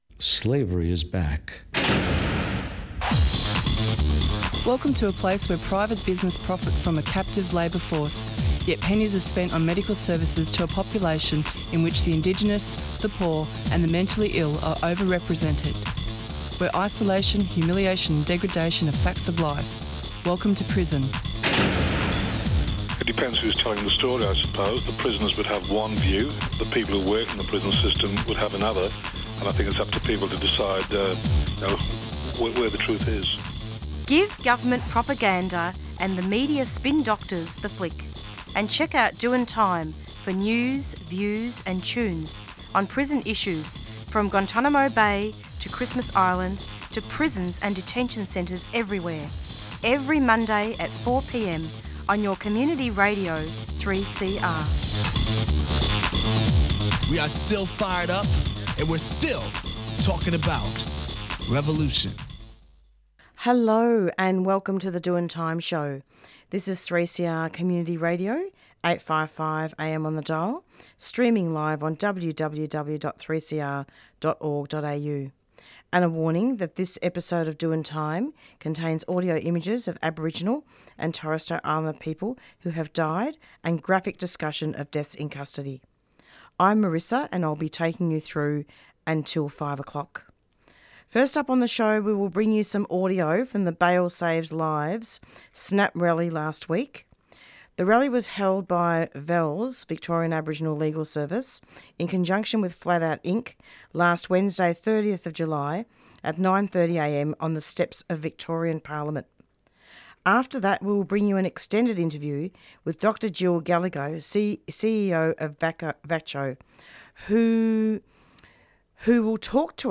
Current affairs